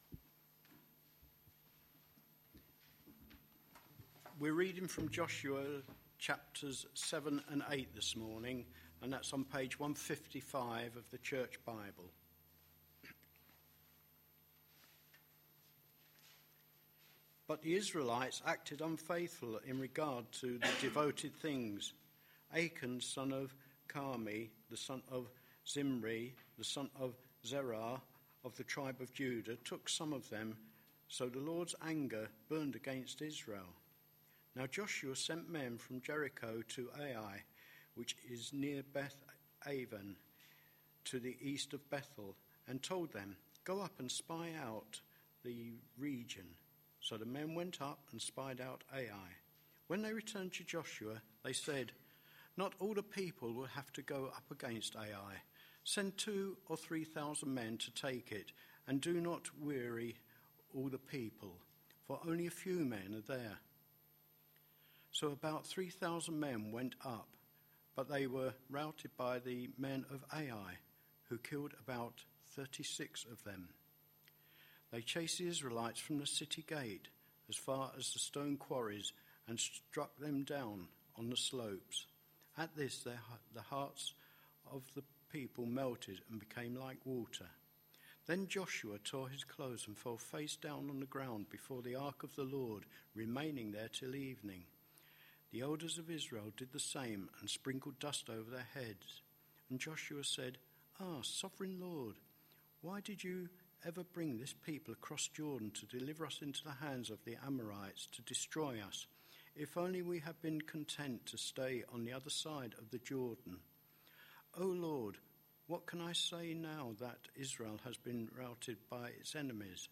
A sermon preached on 6th May, 2012, as part of our Entering God's Rest series.